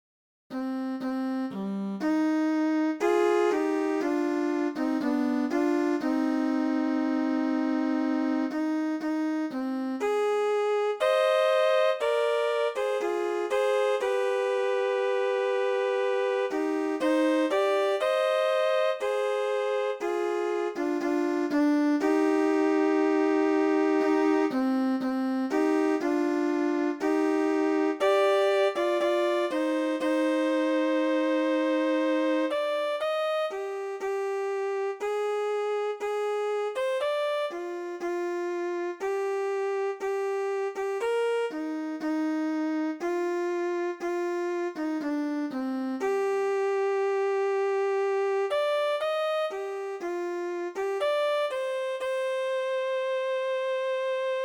Thánh Ca Phụng Vụ